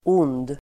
Uttal: [on:d]